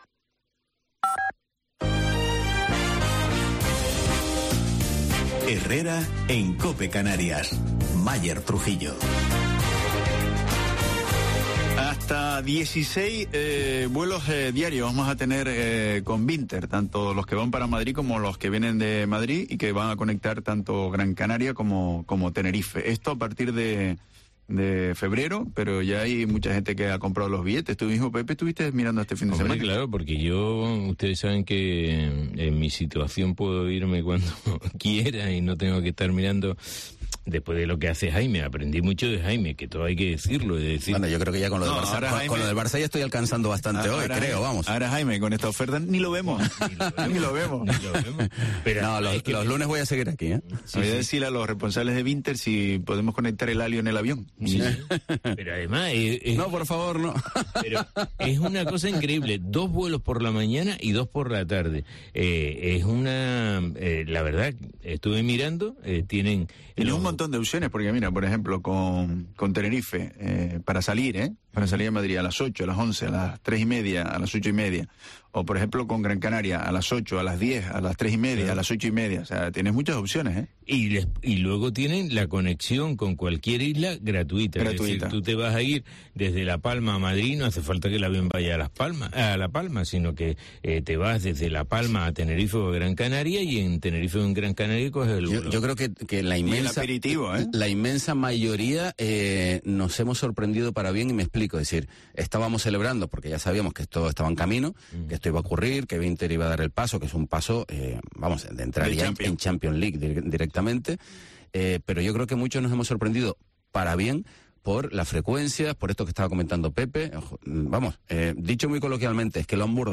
en los micrófonos de Herrera en Canarias